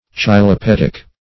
Search Result for " chylopoetic" : The Collaborative International Dictionary of English v.0.48: Chylopoetic \Chy`lo*po*et"ic\, a. [Gr. chylopoiei^n to make into juice, chylo`s juice, chyle + poiei^n to make.]